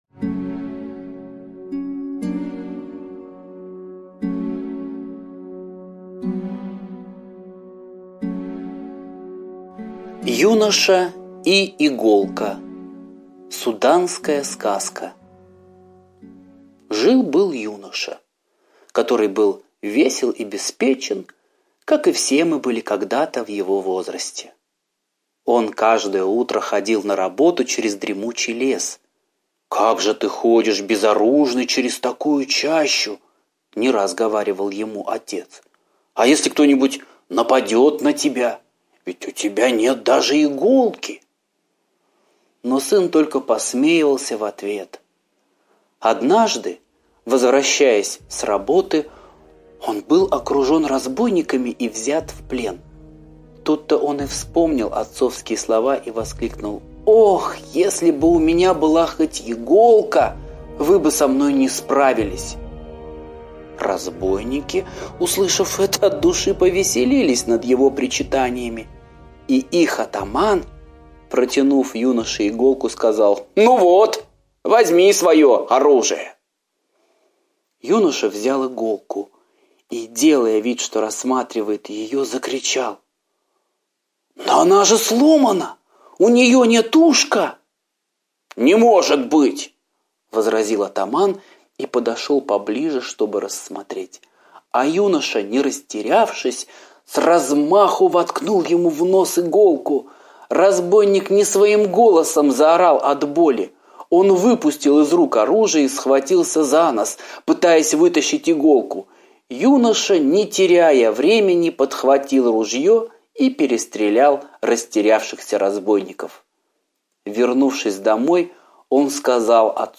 Юноша и иголка - восточная аудиосказка - слушать онлайн